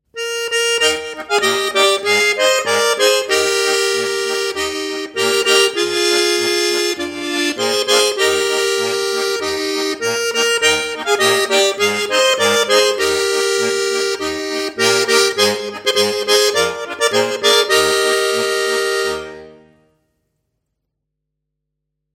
Besetzung: Schwyzerörgeli mit CD